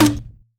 poly_shoot_grenade.wav